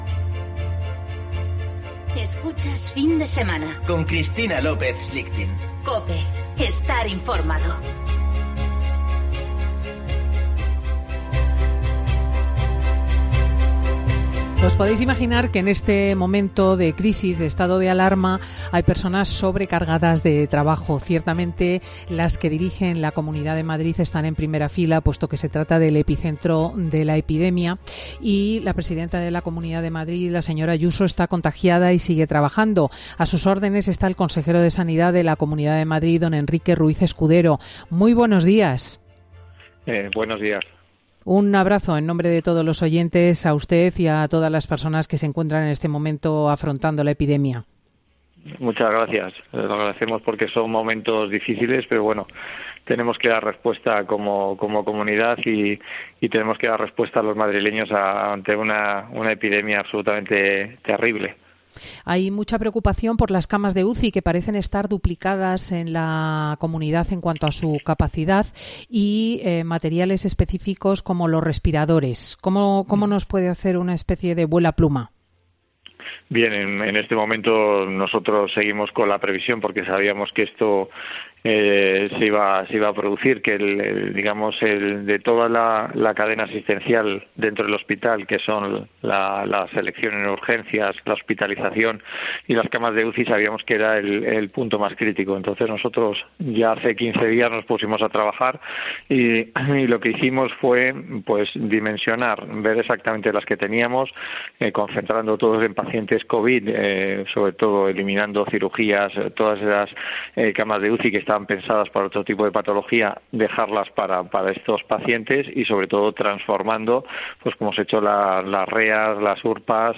Entrevista a Enrique Ruiz Escudero, consejero de Sanidad de Madrid